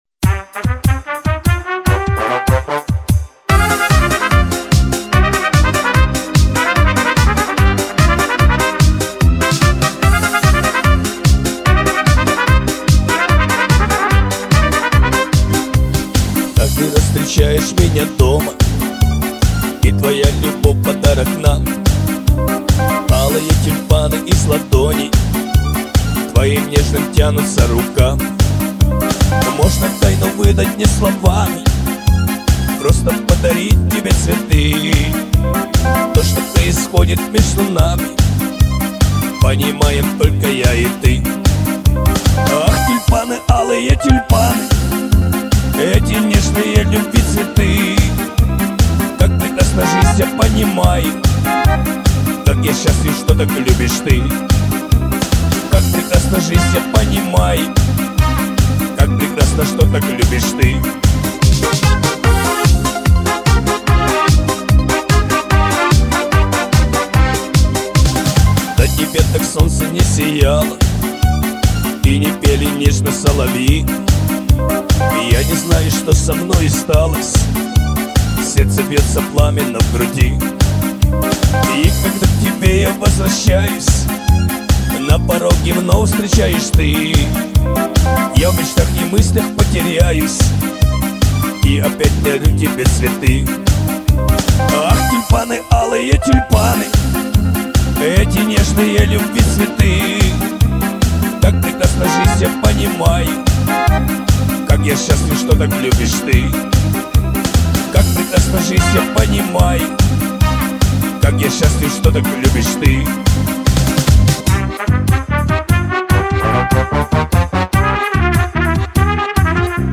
Всі мінусовки жанру Shanson
Плюсовий запис